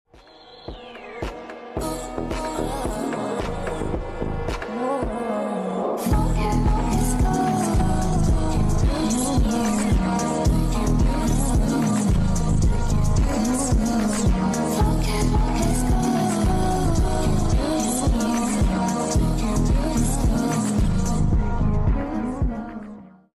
i finally added those flash sound effects free download